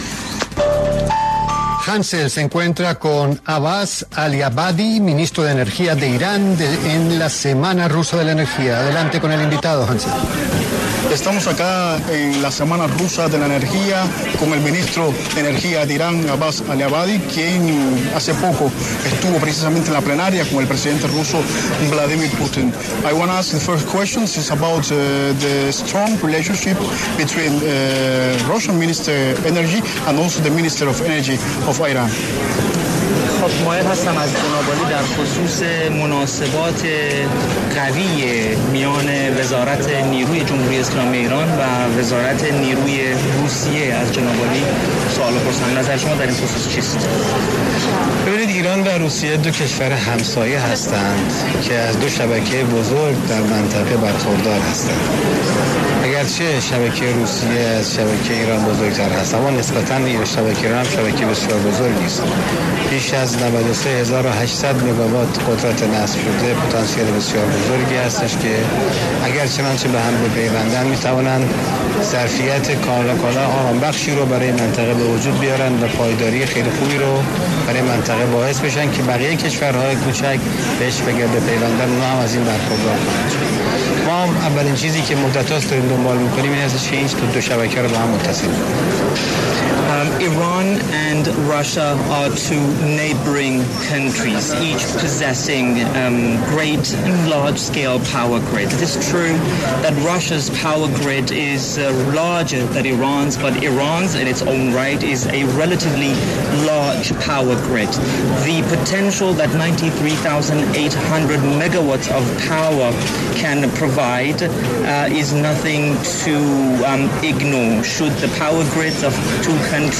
La W hace presencia en Moscú, Rusia, en el marco de la Semana Rusa de la Energía, un evento clave que busca mostrar el potencial del país en y demostrar las perspectivas del complejo ruso de combustibles, energía y cooperación internacional en el sector energético.
En el lugar de la noticia, Abbas Aliabadi, ministro de Energía de Irán, pasó por los micrófonos de La W para hablar del potencial energético de su país y el proyecto nuclear que se encuentran desarrollando.